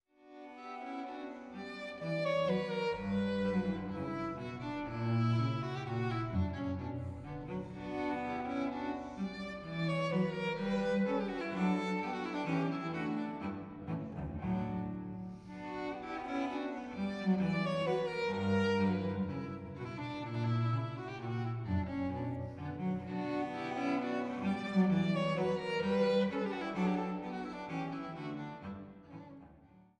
Violine
Violincello